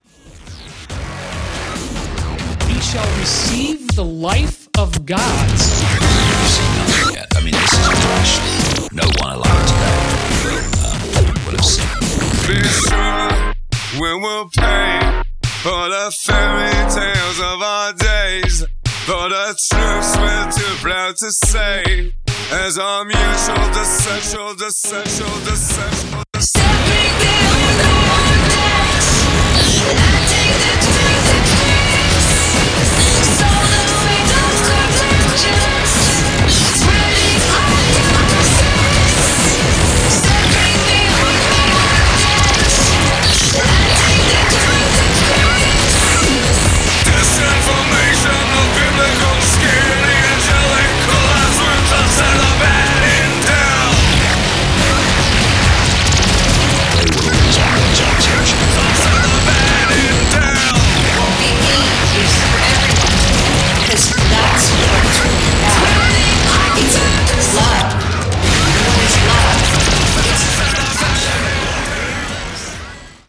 an alternative industrial rock outfit
the British trip hop sound the likes of Massive Attack